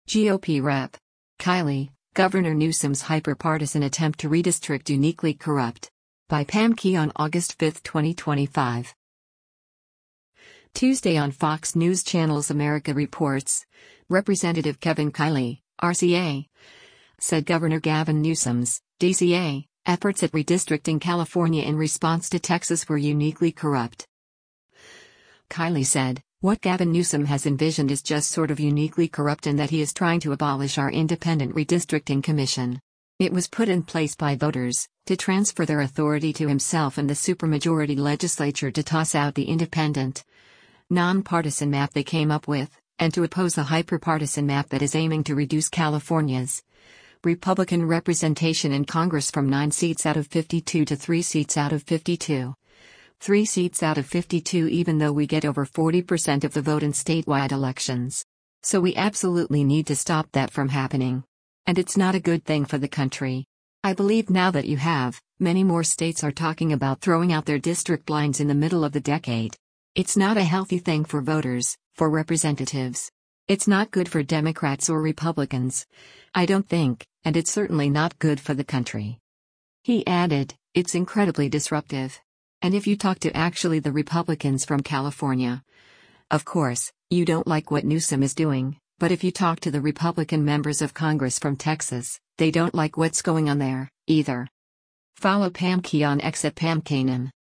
Tuesday on Fox News Channel’s “America Reports,” Rep. Kevin Kiley (R-CA) said Gov. Gavin Newsom’s (D-CA) efforts at redistricting California in response to Texas were “uniquely Corrupt.”